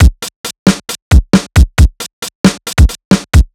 Chugga Break 135.wav